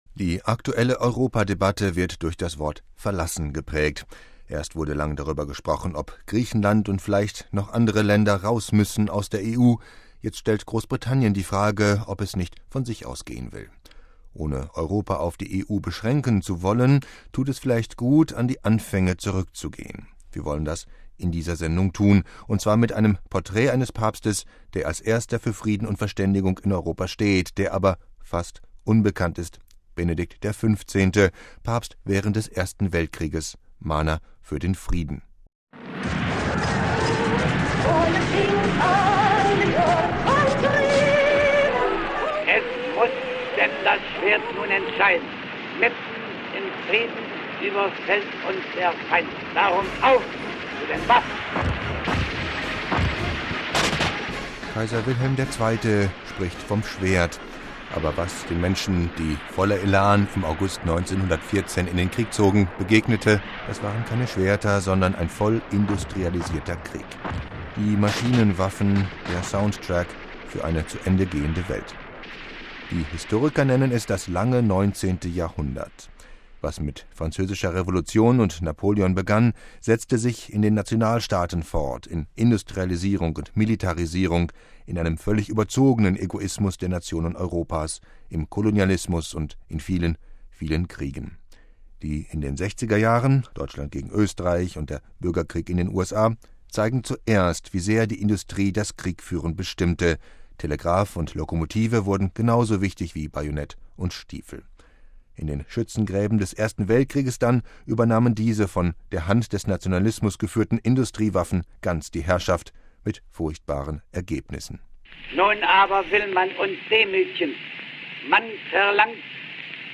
Wenn man Kaiser Wilhelm II. schwadronieren hört, kann man verstehen, wie sehr die Zeit in ihrem verblendeten Nationalismus verhakt war.